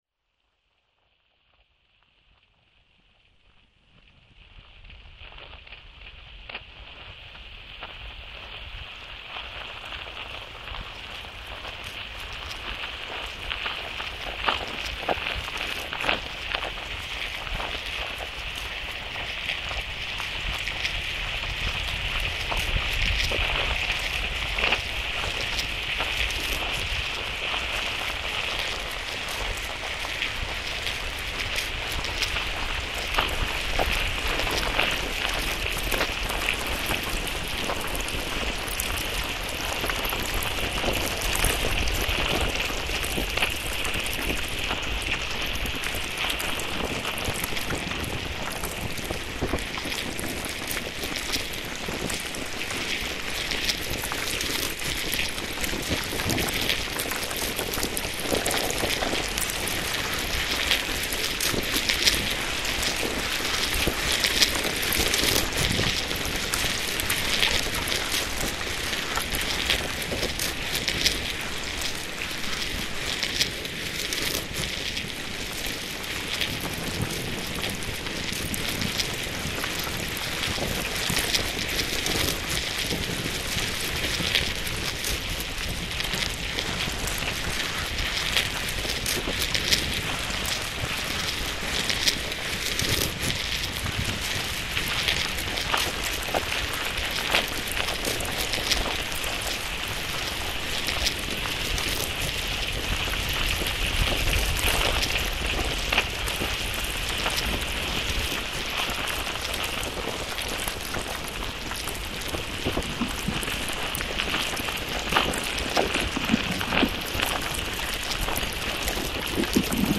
File under: Electroacoustic / Experimental / Dark Ambient